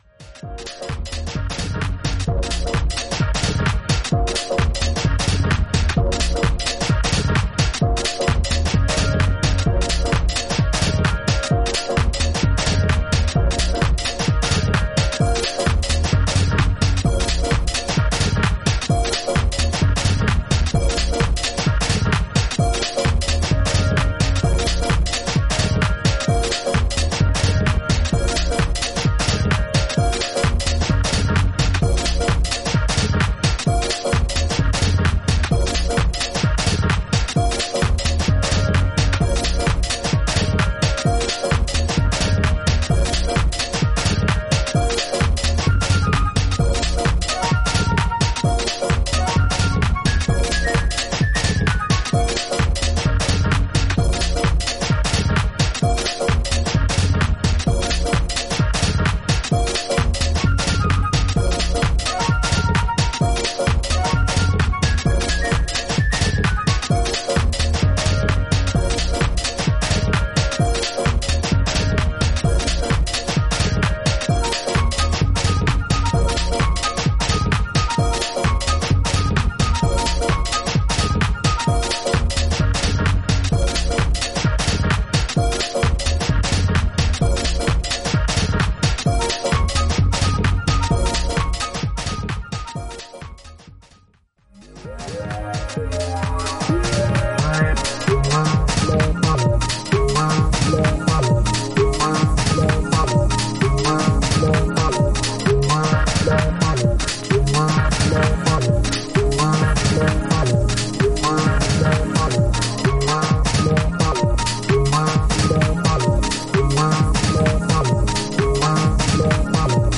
空間的に拡がるシンセが気持ち良い、デトロイト・テクノの影響が色濃く反映したアンダーグラウンド・スピリッツ溢れる作品です。